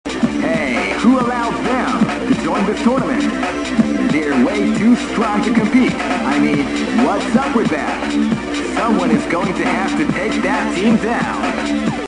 Now the announcer tries to sound deep and philisophical, but AGAIN, fails miserably |